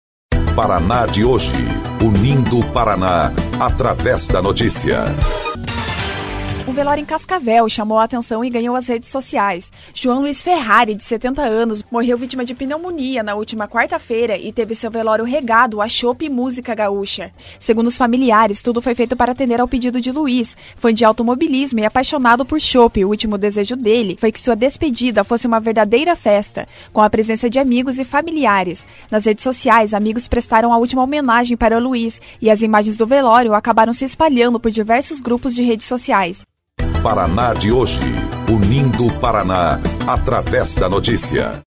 28.12 – BOLETIM – Velório regado a chope e música chama a atenção nas redes sociais